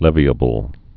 (lĕvē-ə-bəl)